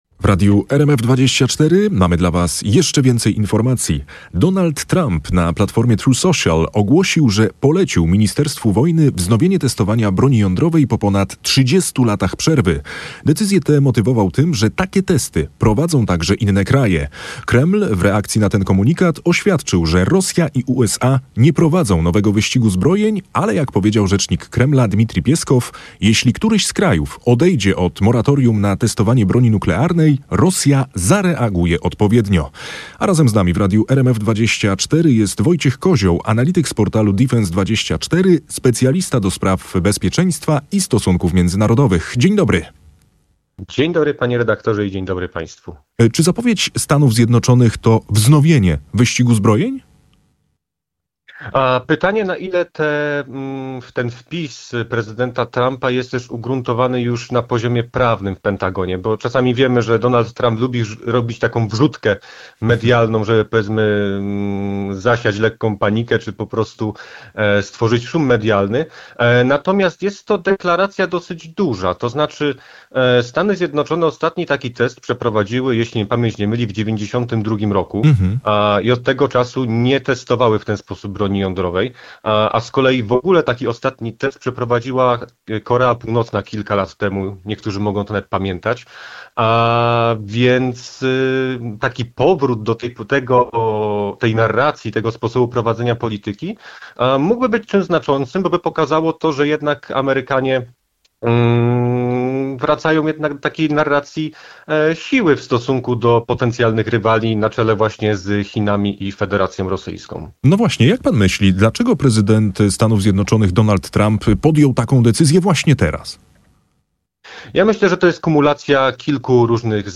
Odsłuchaj starsze transmisje RMF FM!
18:00 Fakty i Popołudniowa rozmowa w RMF FM - 30.10.2025